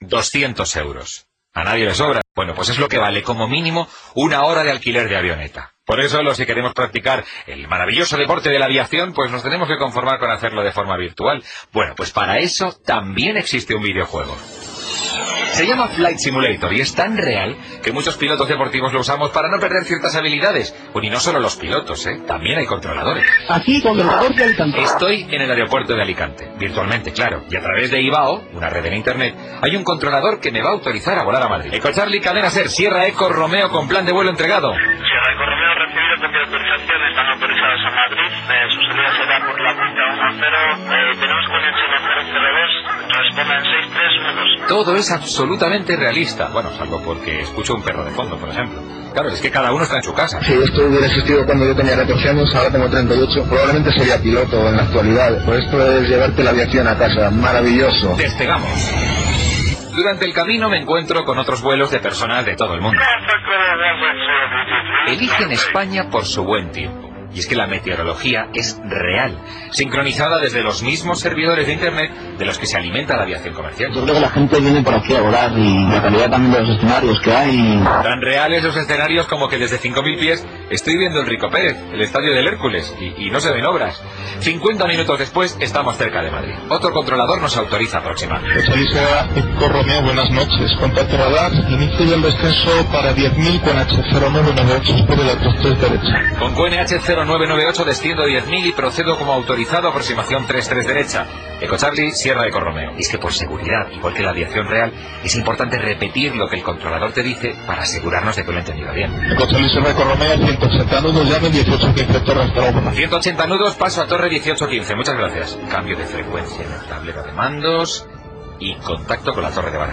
Info-entreteniment
Programa presentat per Carles Francino.